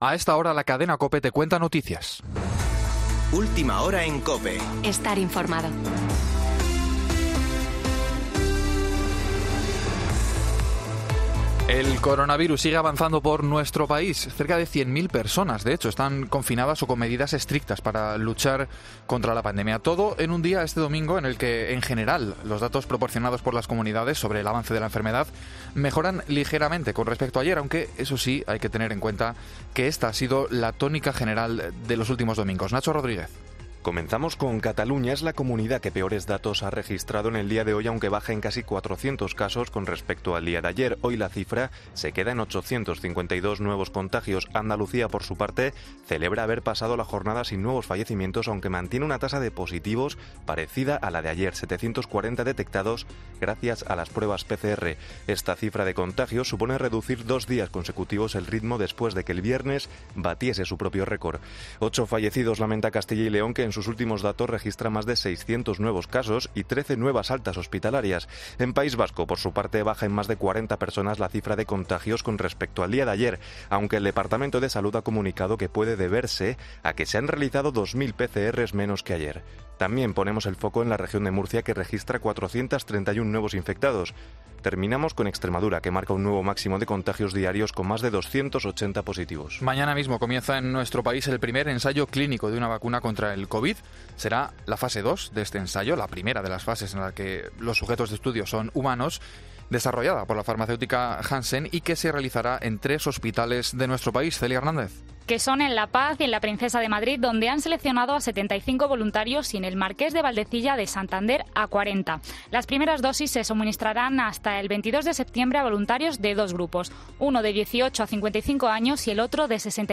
AUDIO: Boletín de noticias de COPE del 13 de septiembre de 2020 a las 18.00 horas